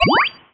collect_item_13.wav